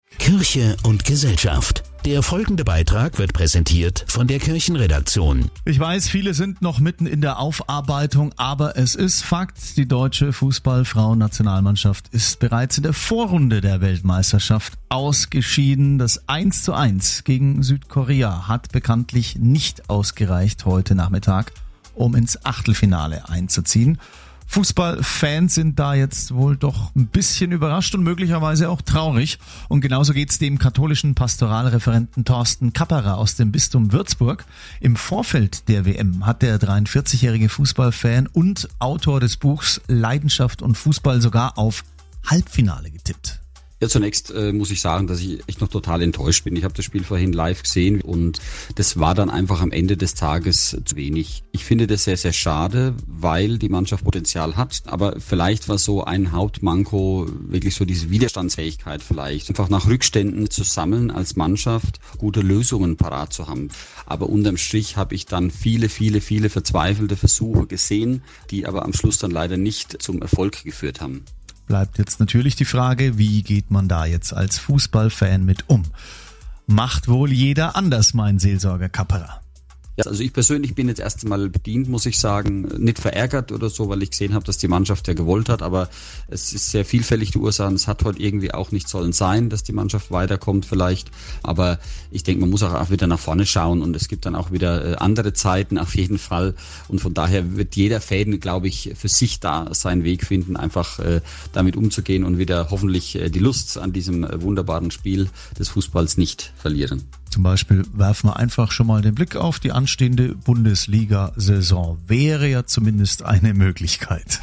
Radio-Interview zum FrauenfußballAugust 2023